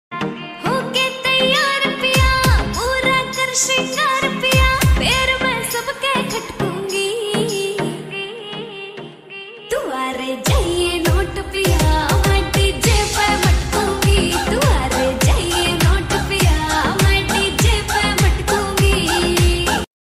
New Haryanvi Song